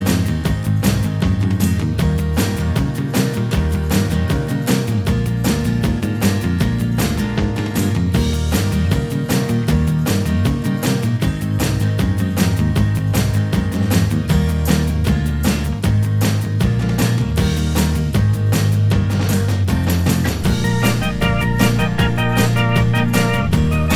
Minus Lead Guitar Pop (1960s) 2:24 Buy £1.50